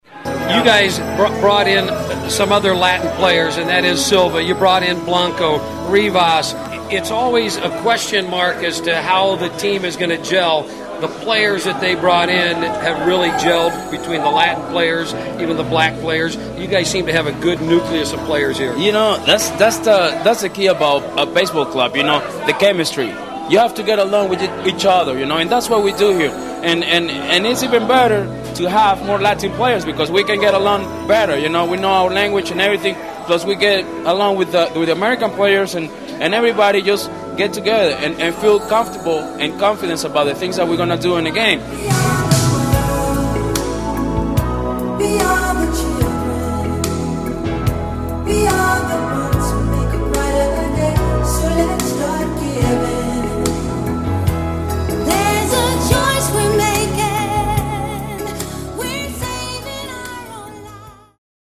Another Victory '04 Campaign Speech
Dan Gladden and Johan Santana on the diversity of the team. With a special guest chorus.